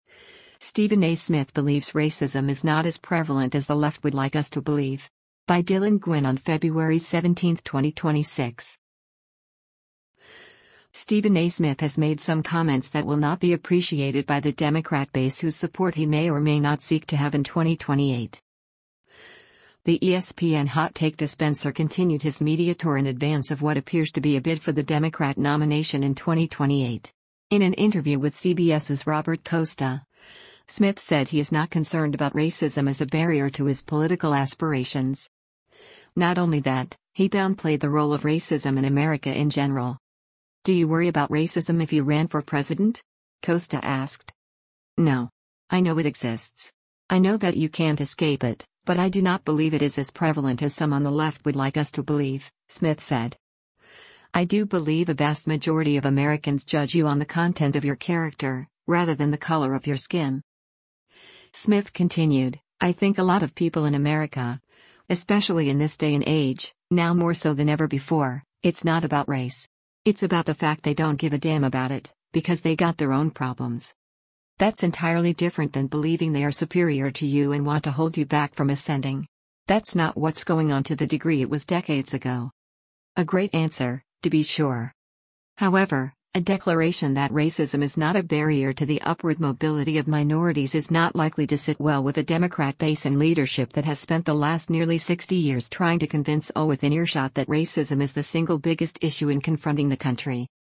In an interview with CBS’s Robert Costa, Smith said he is not concerned about racism as a barrier to his political aspirations.